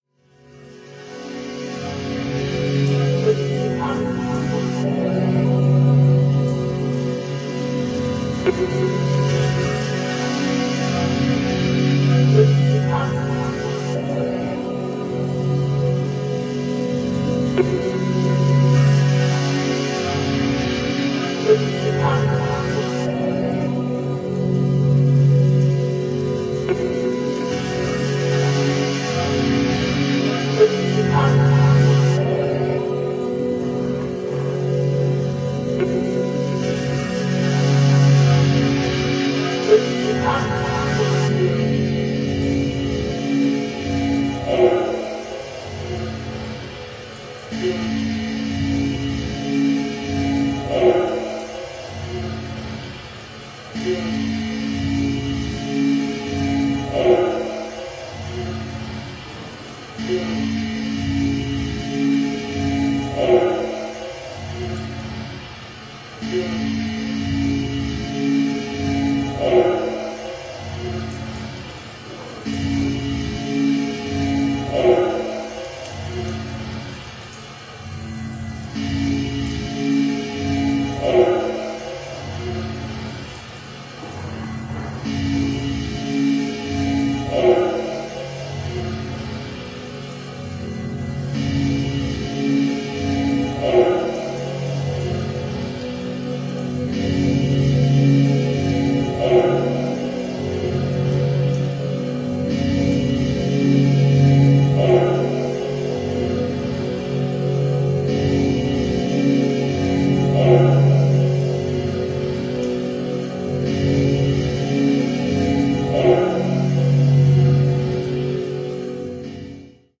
forward thinking house jams